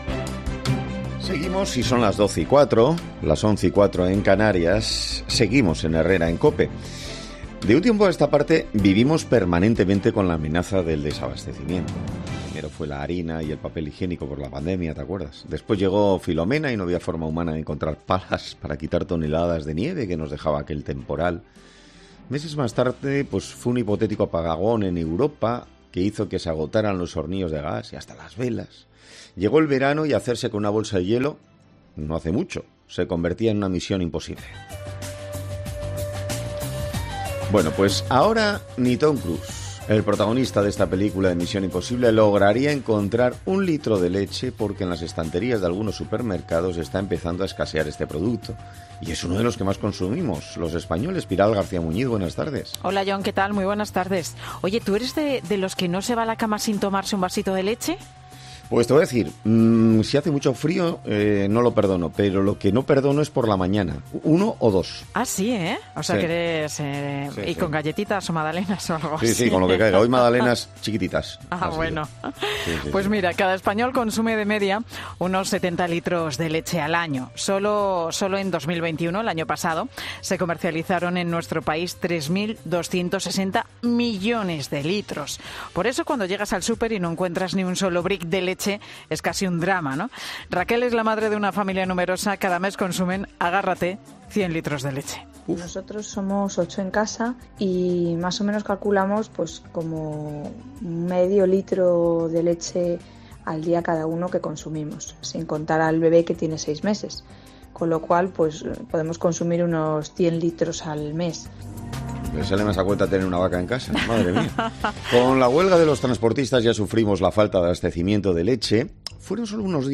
En 'Herrera en COPE' hemos hablado con diferentes ganaderos que nos han explicado el motivo de la escasez de leche en los supermercados